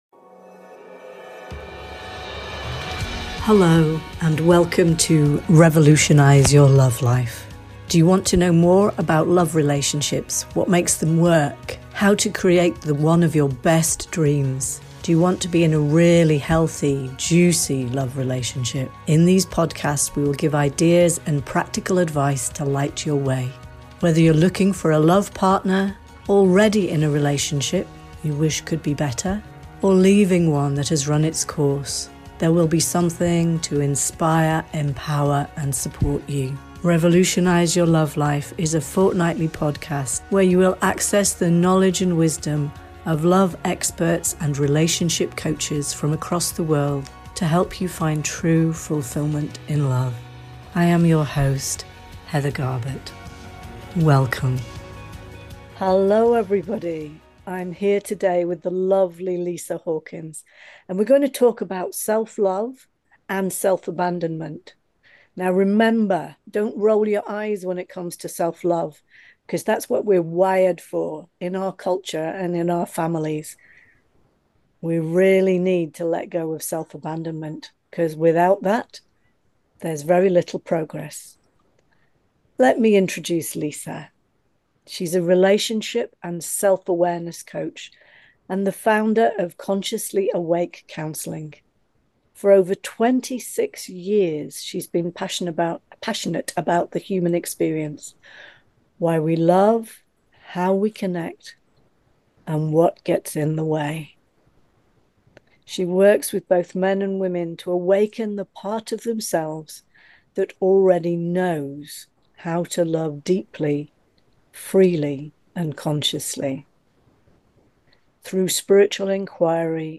In this heart-opening conversation